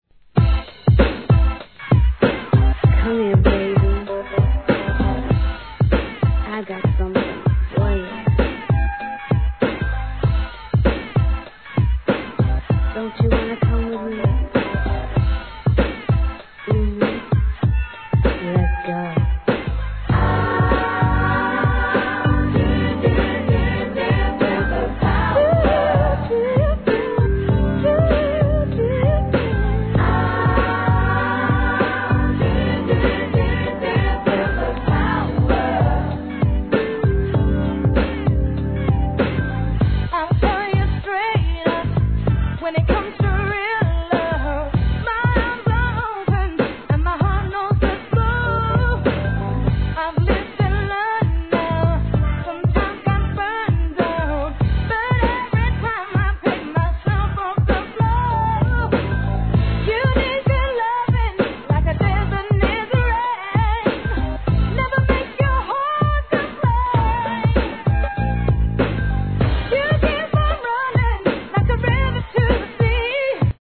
90'S R&Bクラシック、大人気パーティーチューン！